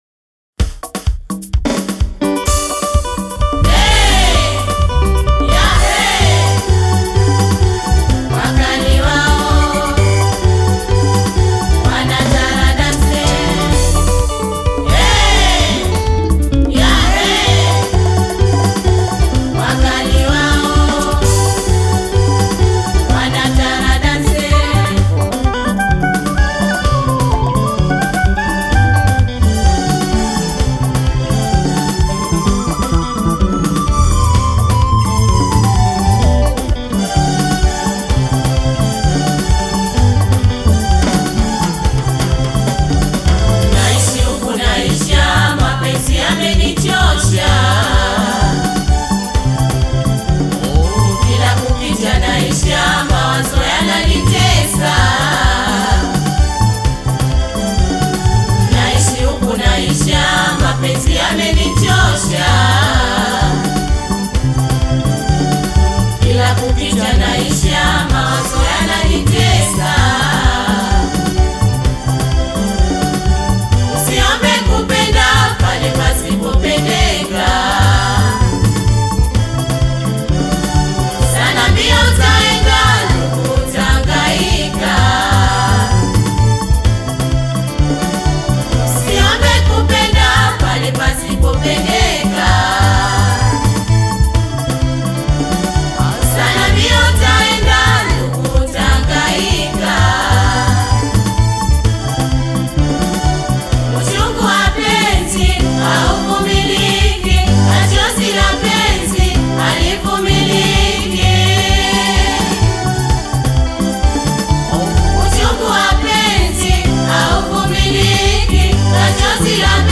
taarab music